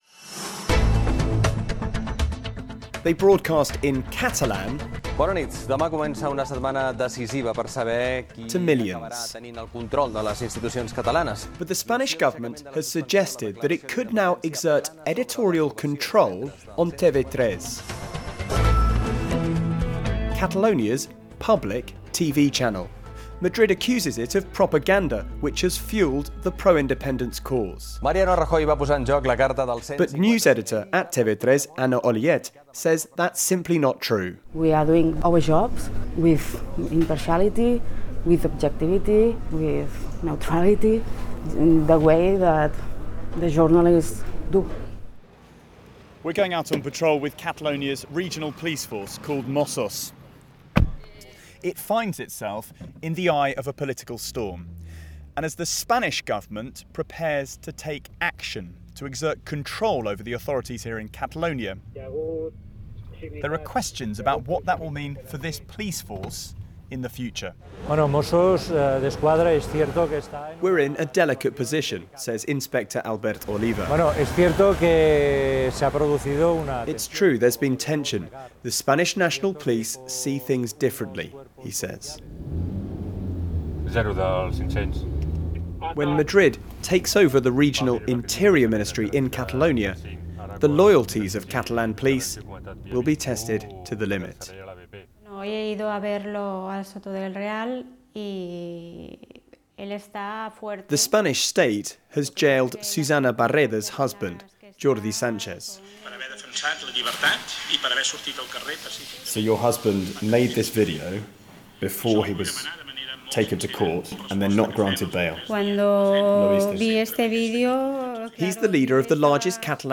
BBC Radio 4 pkg from Barcelona - what will Madrid's plan to assert control over Catalan institutions really mean in practise?